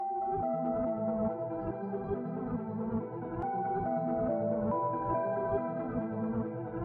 描述：小小的毛茸茸的节拍 小小的半速 小小的面具 你已经知道了
Tag: 140 bpm Trap Loops Synth Loops 1.15 MB wav Key : Unknown